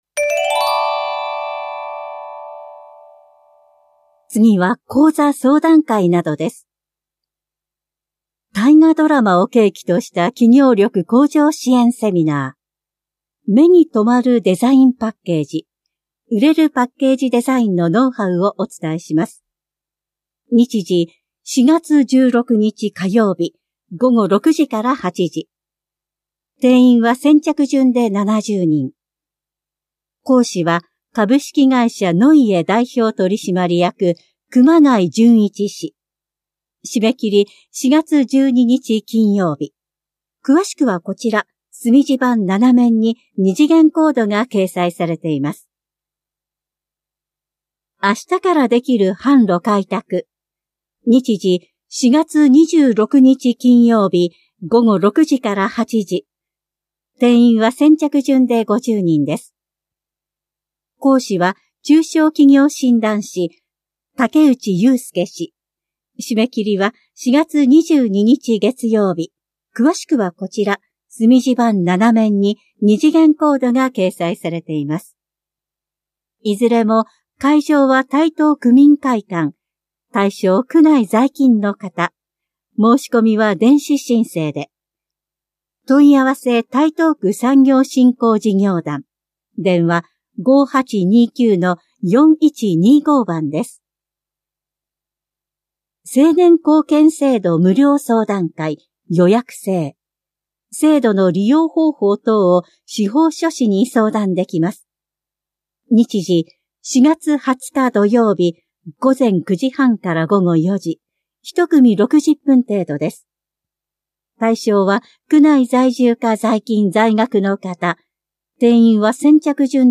広報「たいとう」令和6年3月20日号の音声読み上げデータです。